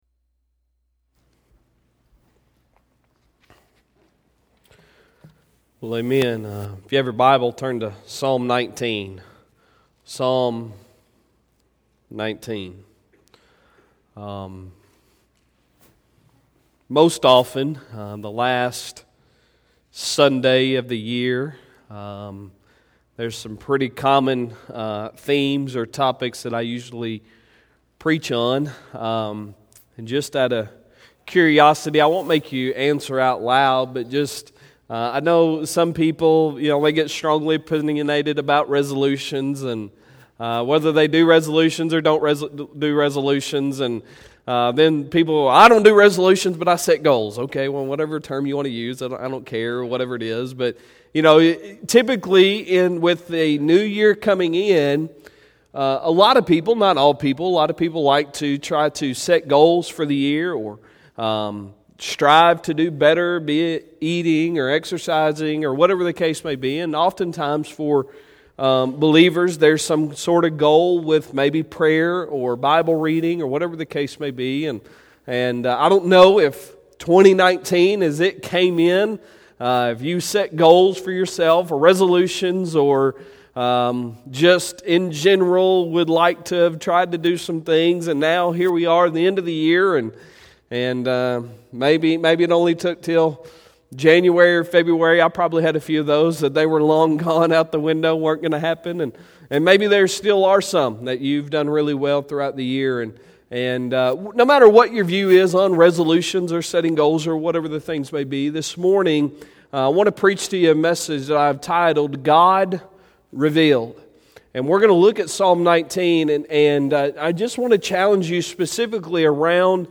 Sunday Sermon December 29, 2019